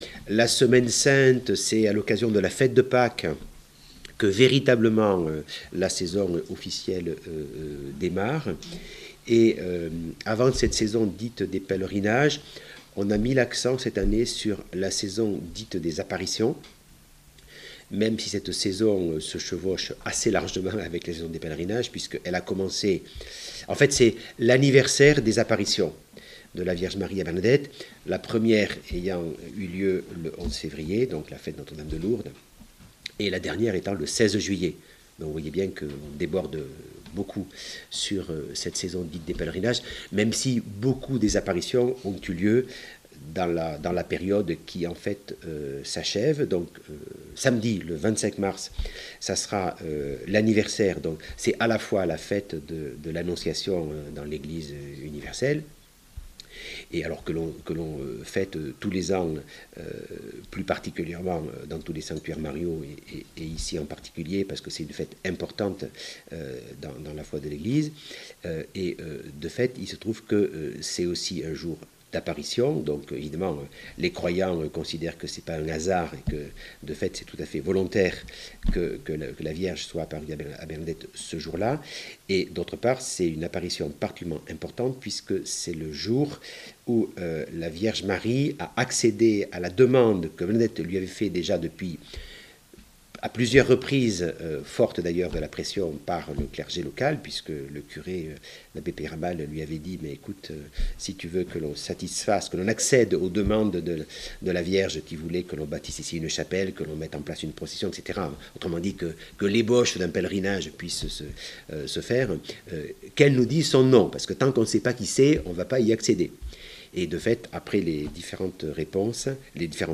Interview Reportage